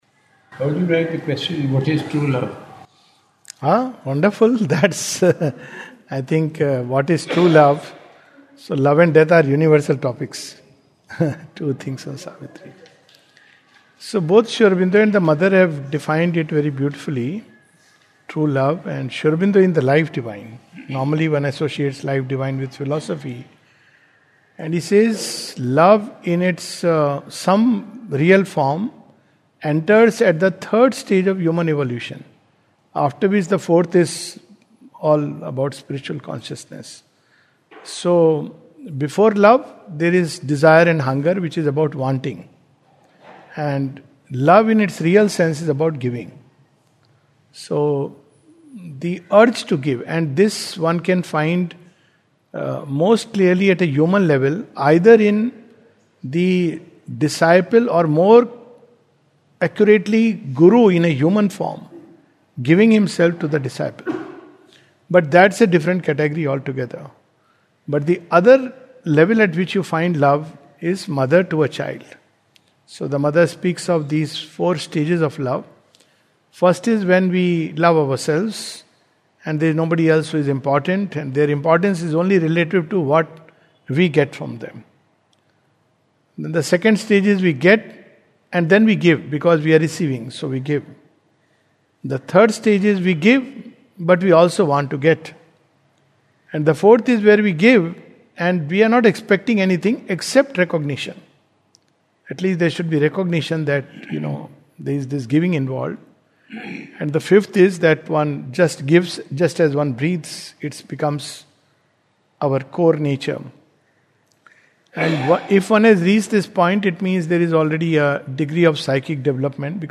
This is an interactive session at Matruniketan. The questions asked were: What is Love? Brain hacking Artificial Intelligence Spiritual Evolution Crossing over after Death